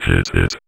VVE1 Vocoder Phrases
VVE1 Vocoder Phrases 27.wav